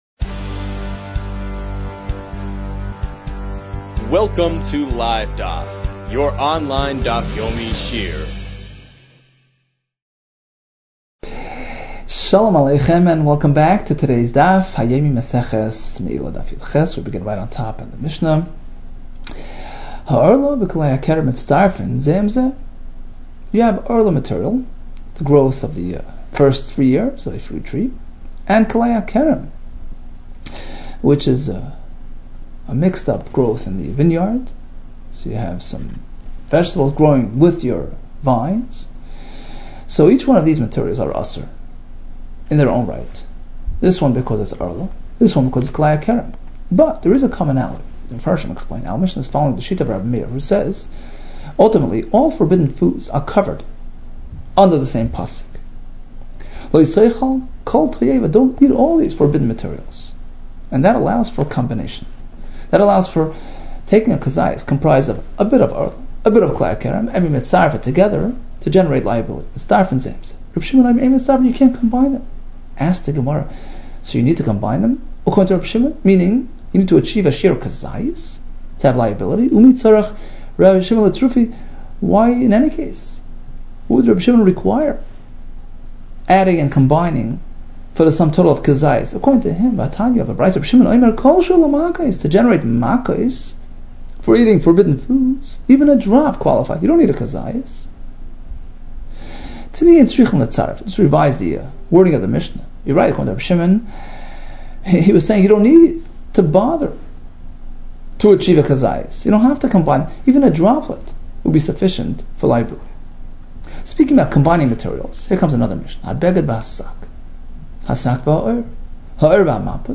Meilah 17 - מעילה יז | Daf Yomi Online Shiur | Livedaf